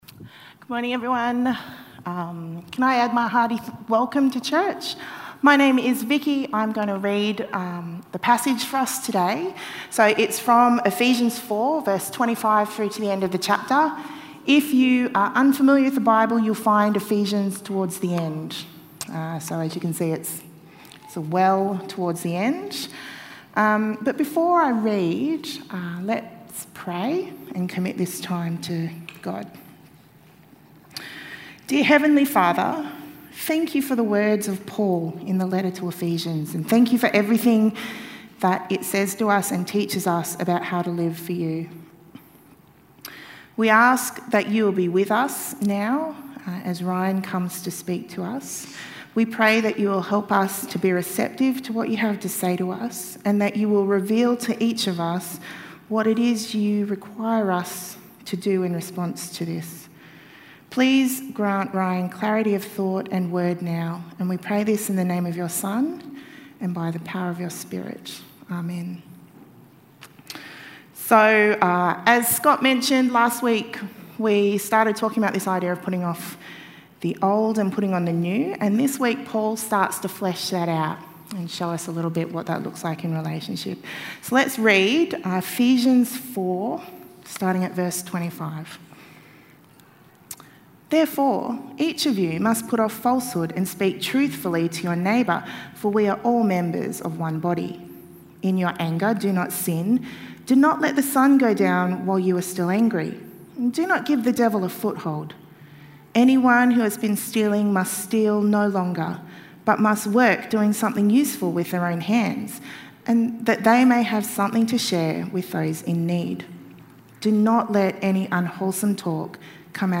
HealthyRelationships-ReadingaAndTalk.mp3